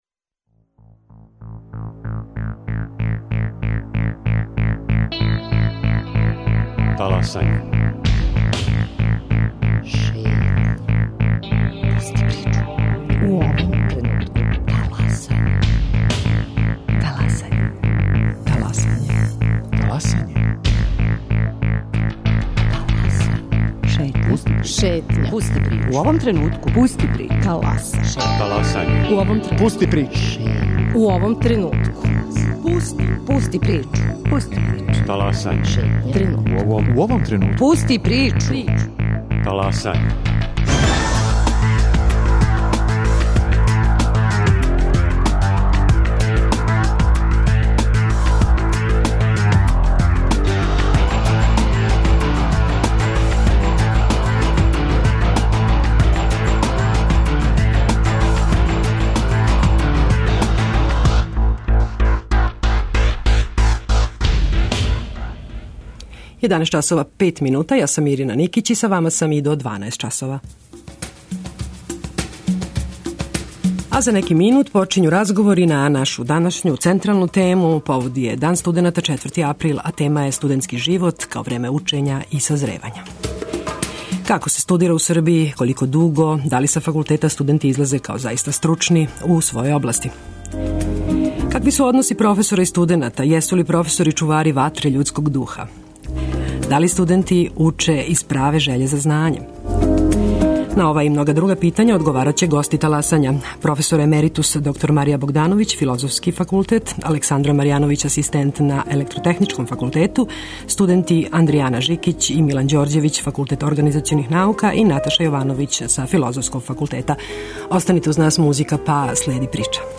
На ова и многа друга питања одговарају гости Таласања, професори и студенти Београдског универзитета.